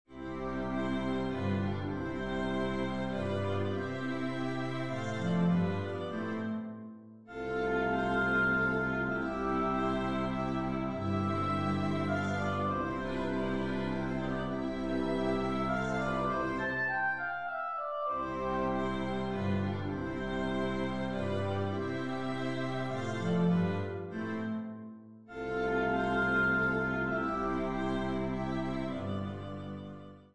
Singspiel
Orchester-Sound